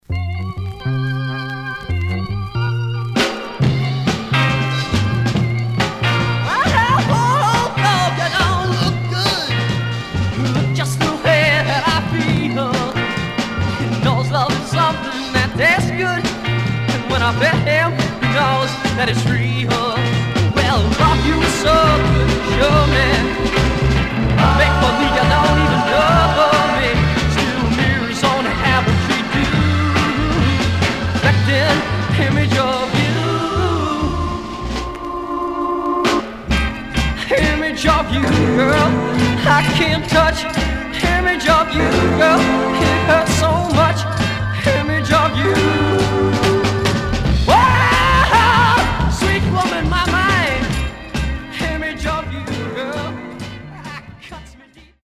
Psych With Fuzz Break Condition: M- SOL DJ
Stereo/mono Mono
Garage, 60's Punk